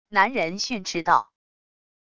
男人训斥道wav音频